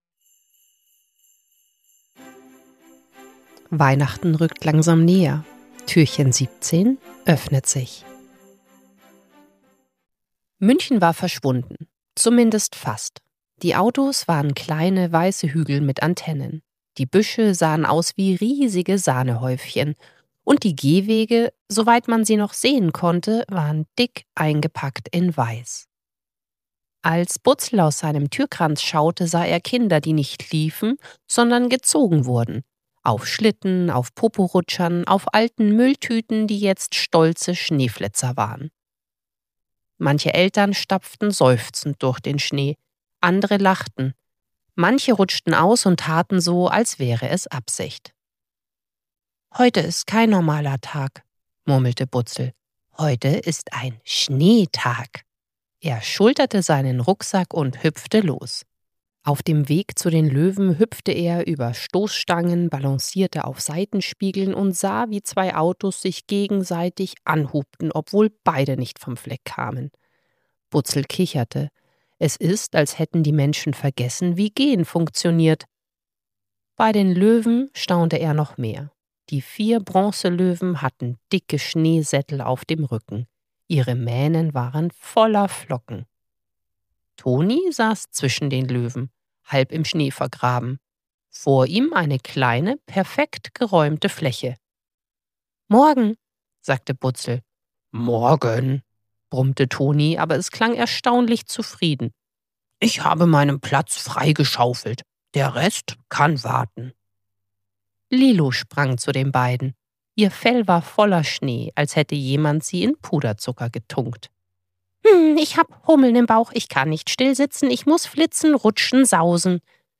17. Türchen – Butzel und der Schneetag ~ Butzels Adventskalender – 24 Hörgeschichten voller Herz & kleiner Wunder Podcast